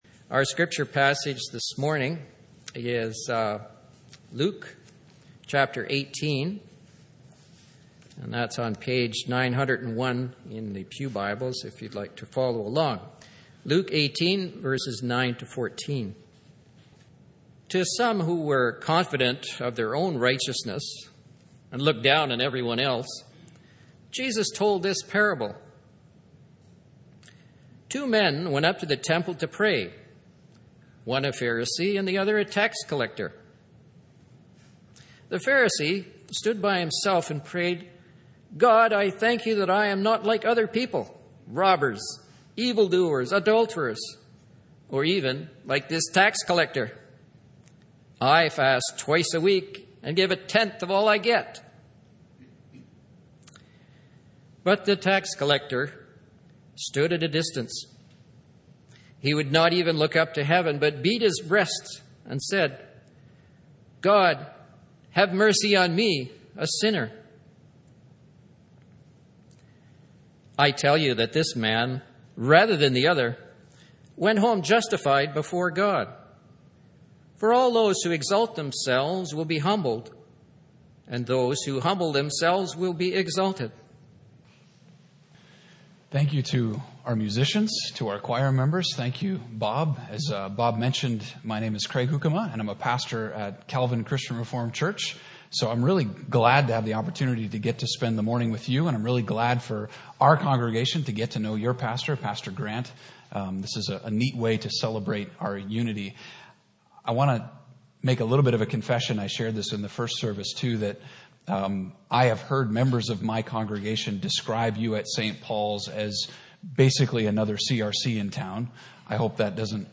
Message
A message from the series "Fall 2019."